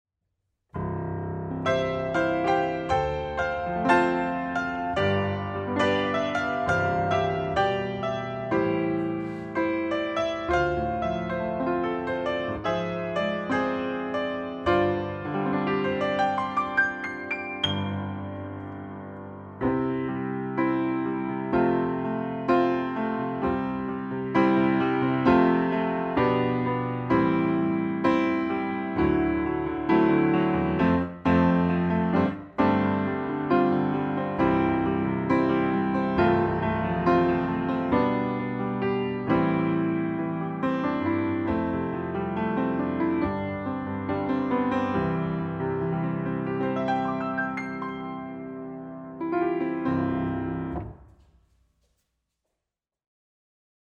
Микрощелчки при записи рояля
Непонятные щелчки на записи рояля. Оо микрофонов не зависит.